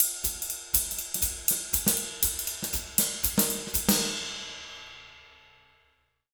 240SWING05-L.wav